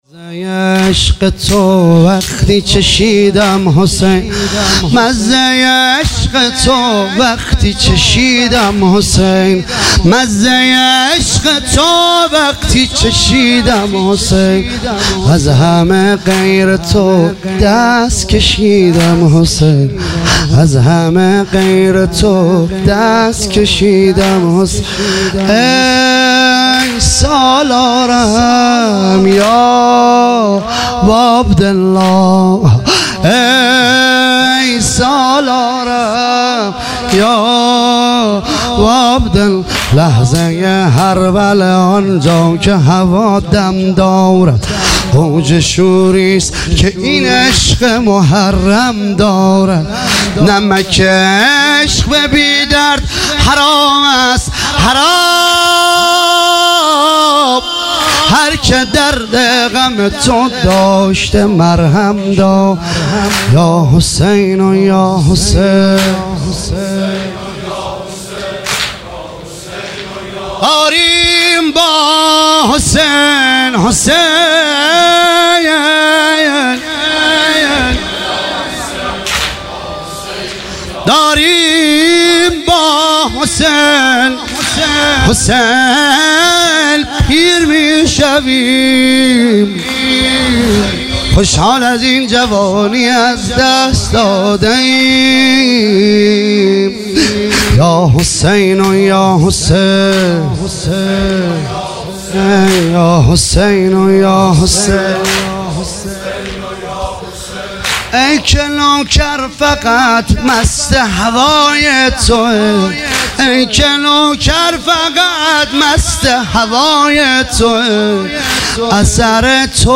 واحد تند